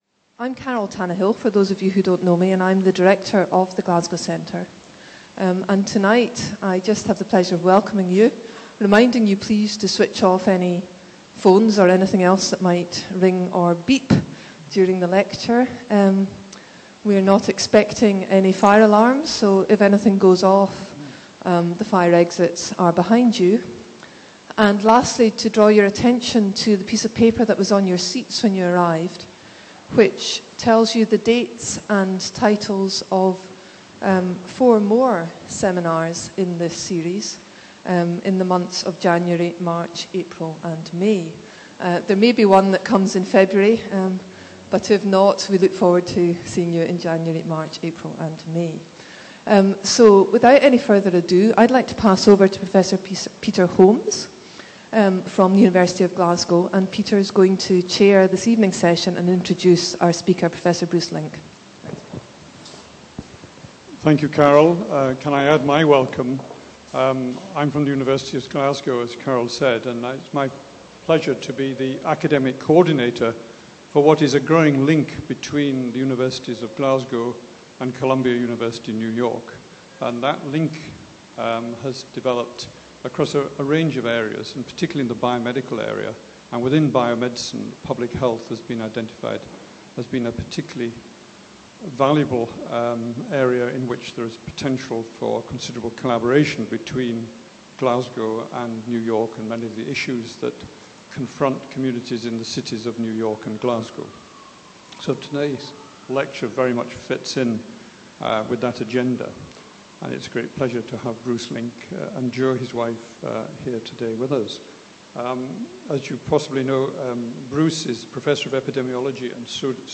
Seminar Series 4: Lecture 1